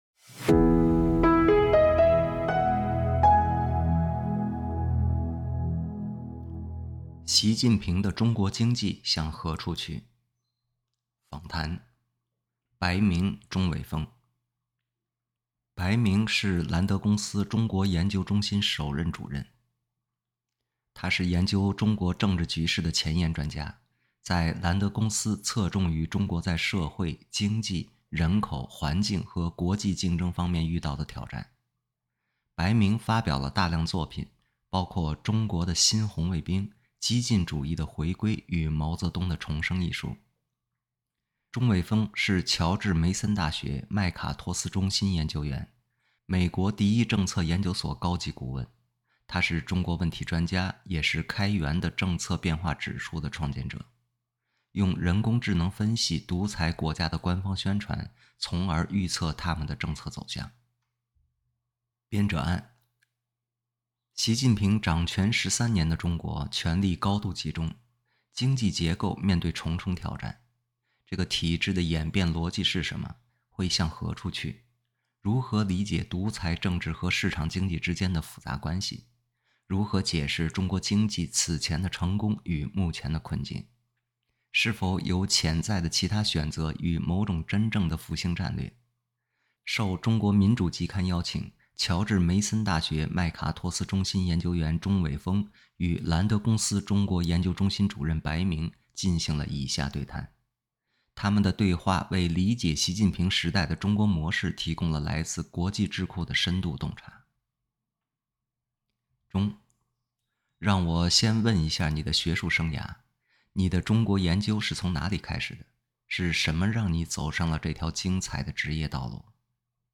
进行了以下对谈。